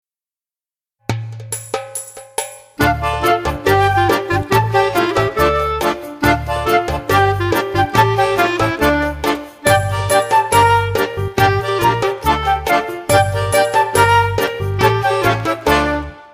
36 pfiffige Lieder in Mundart und Hochdeutsch